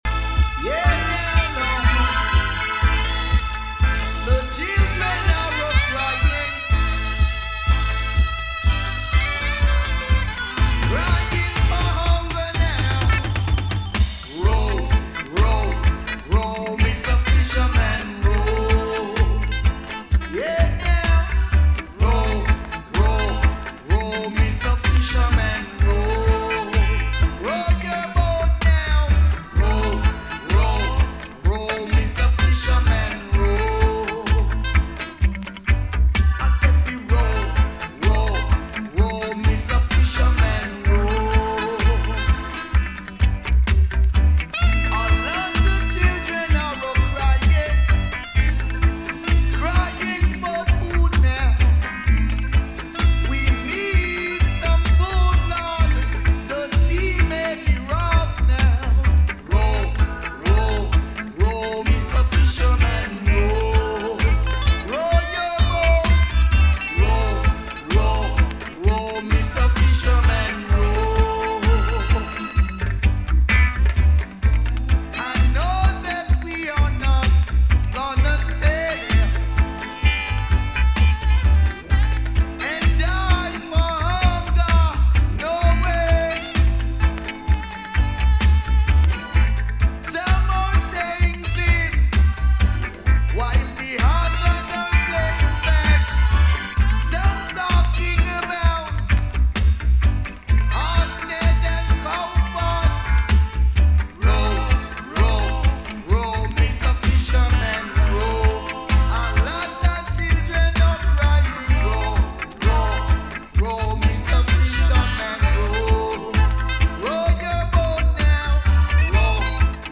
Some recording issues this week so you have only the first 40mins (part 1) and last 40 mins (part 2) available